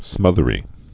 (smŭthə-rē)